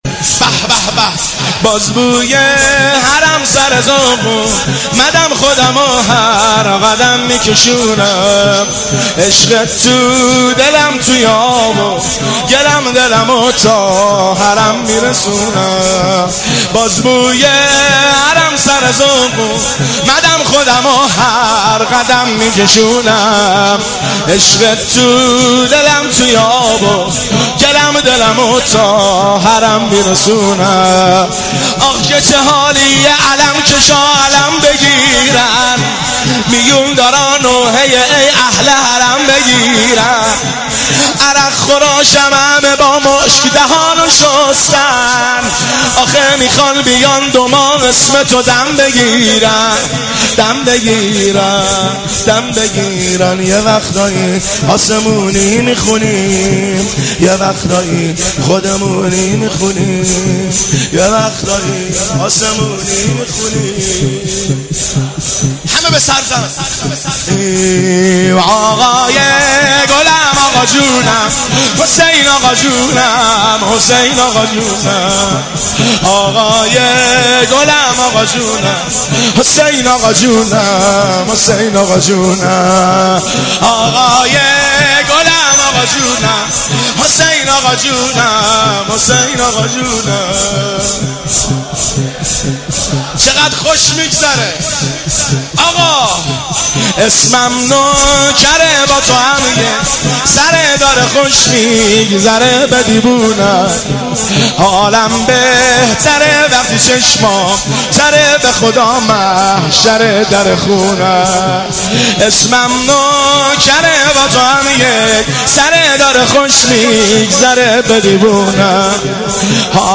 هیئت بین الحرمین طهران شب هفتم محرم97